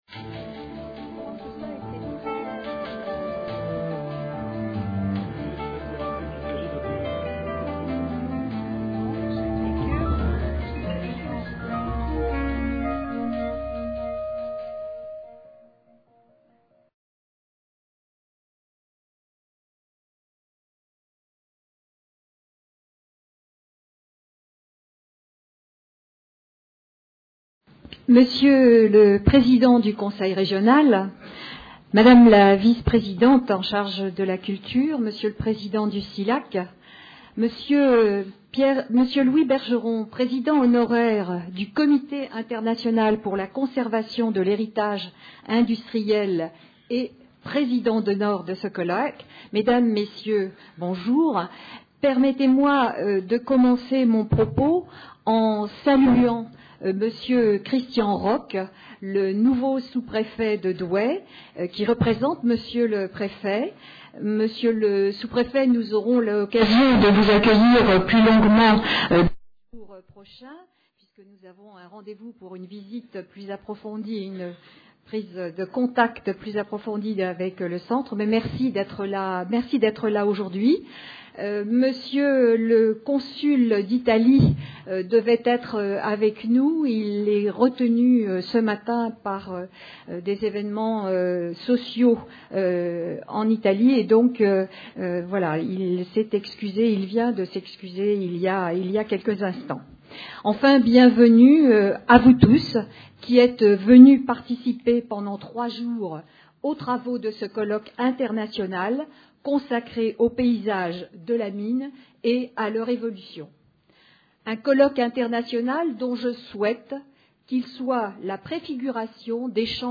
Les paysages de la mine, un patrimoine contesté - Allocutions d'ouverture | Canal U
Ce colloque organisé par le CILA (Comité d’Information et de Liaison pour l’Archéologie, l’étude et la mise en valeur du patrimoine industriel) et le Centre Historique Minier s’adresse aux universitaires (historiens, géographes, etc.), responsables politiques, ingénieurs, industriels, aménageurs, urbanistes, architectes, paysagistes, acteurs du patrimoine, étudiants...